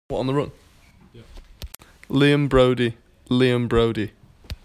liam-broady-name-pronunciation.mp3